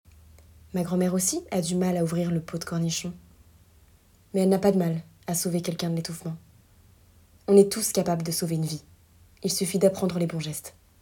- Mezzo-soprano